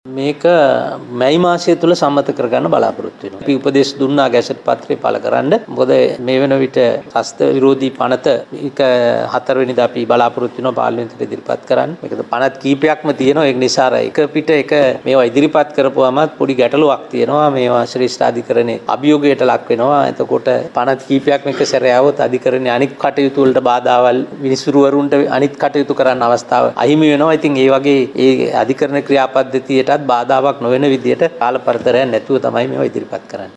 අධිකරණ අමාත්‍යංශයේ පැවති මාධ්‍ය හමුවකට එක්වෙමින් අමාත්‍යවරයා මේ බව ප්‍රකාශ කළා.